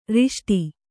♪ riṣṭi